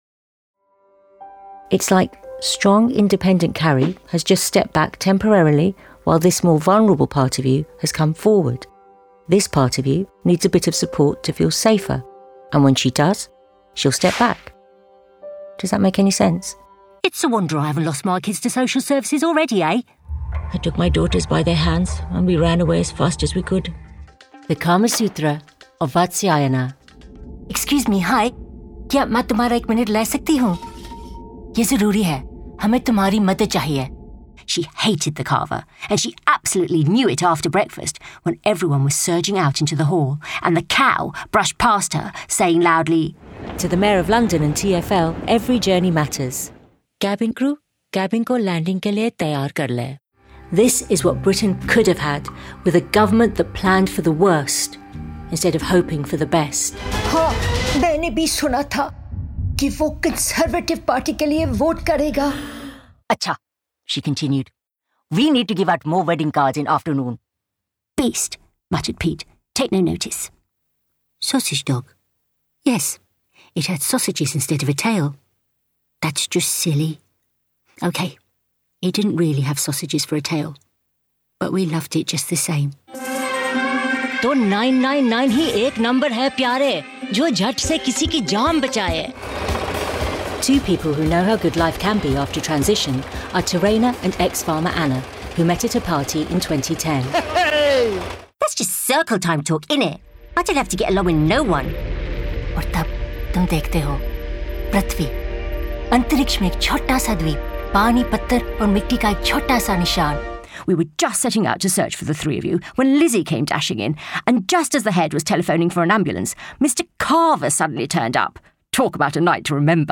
RP + British Indian (Hindi, Punjabi). Friendly, warm, reassuring, youthful, approachable, natural | Rhubarb Voices